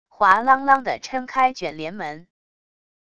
哗啷啷的抻开卷帘门wav音频